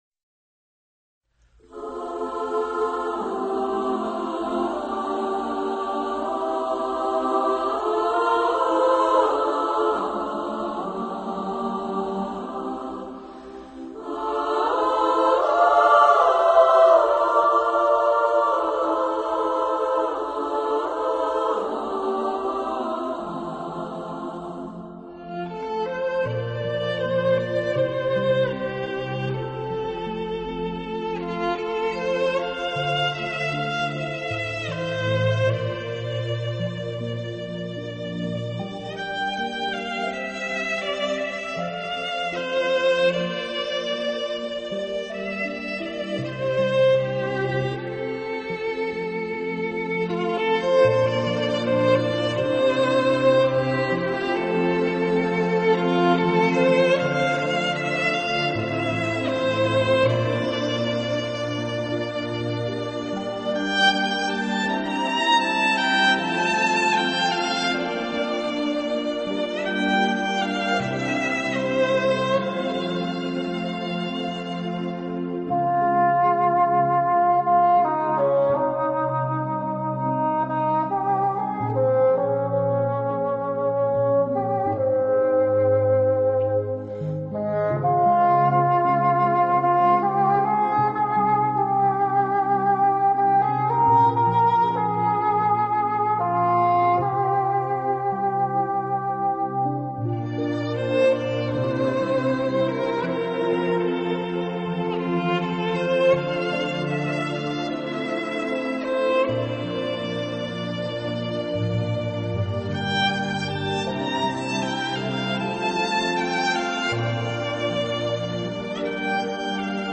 的高贵气质和如同丝绸般的笼罩在我们的心上，柔柔的，滑滑的舒服和享用！
有着令人愉快的优美旋律，有时候这胜过感情脆弱的改编乐曲。